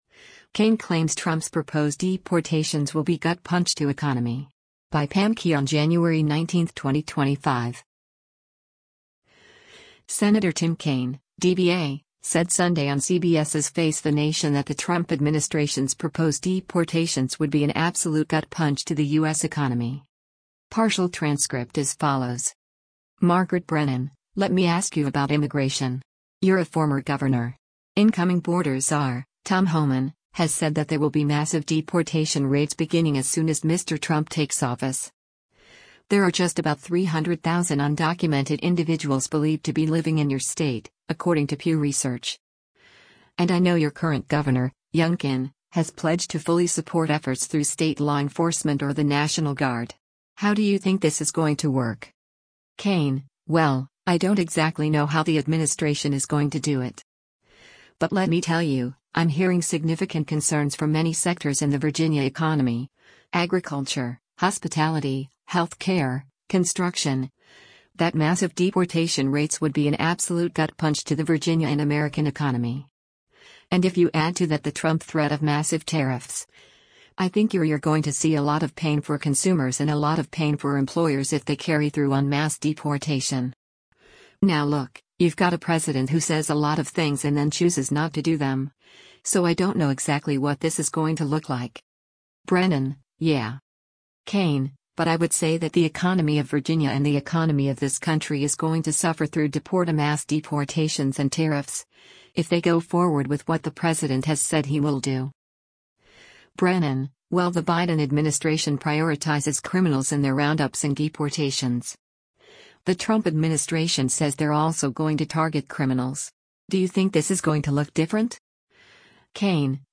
Senator Tim Kaine (D-VA) said Sunday on CBS’s “Face the Nation” that the Trump administration’s proposed deportations would be “an absolute gut punch” to the U.S. economy.